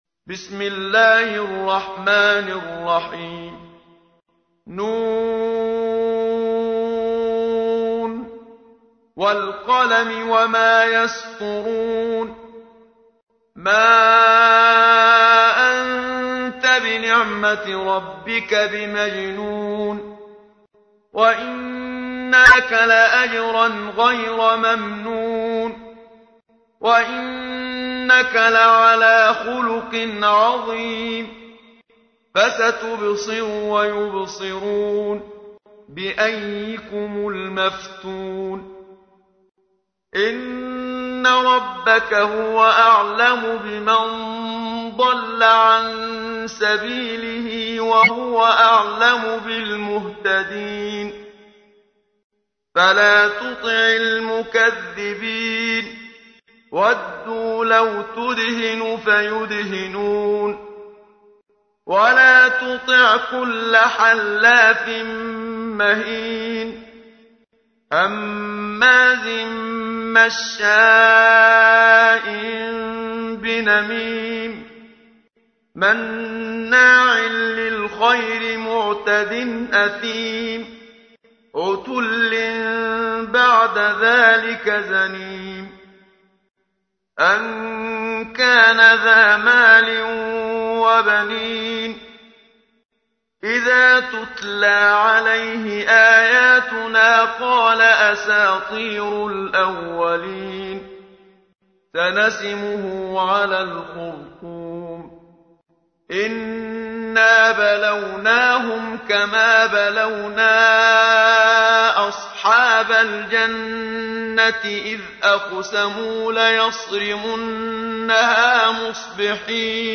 تحميل : 68. سورة القلم / القارئ محمد صديق المنشاوي / القرآن الكريم / موقع يا حسين